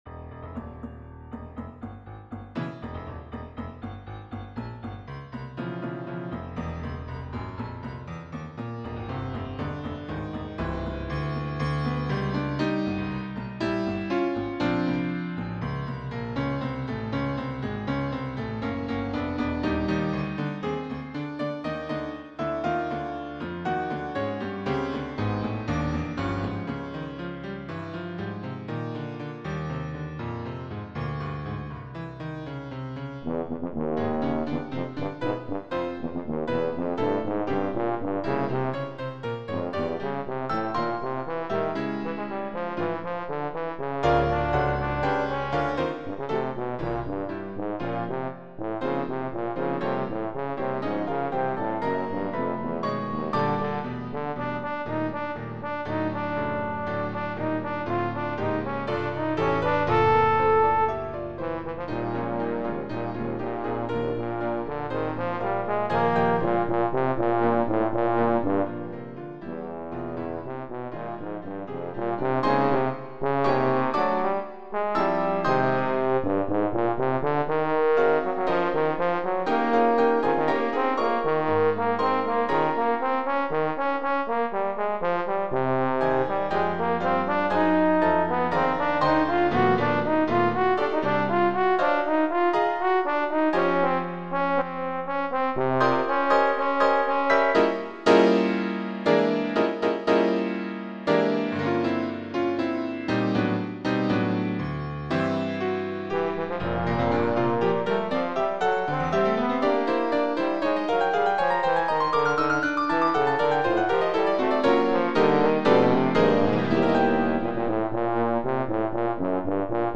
Posaune, Klavier, ,